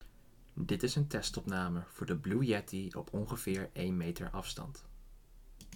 Blue Yeti - 0% gain - Cardioid - 100 cm afstand
Blue-Yet-1-meter.m4a